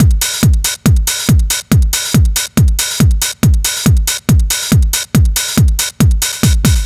NRG 4 On The Floor 041.wav